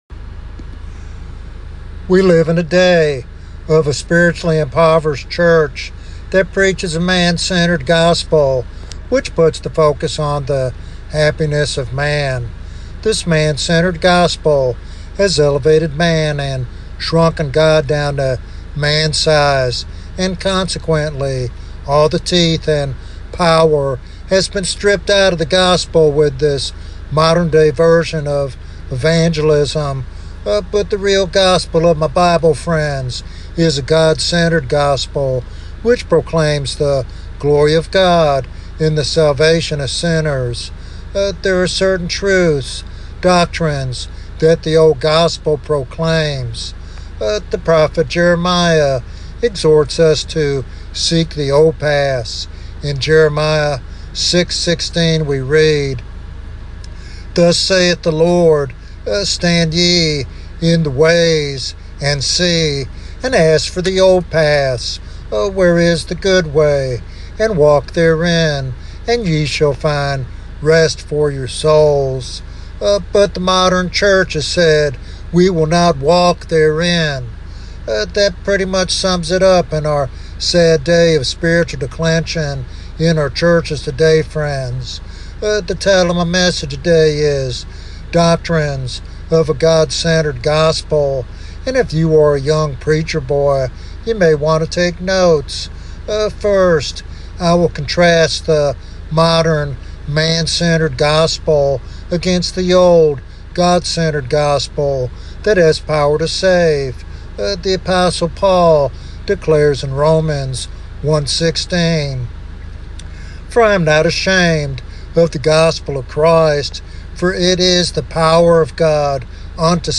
This sermon is a vital reminder of the power and glory of the gospel as revealed in Scripture.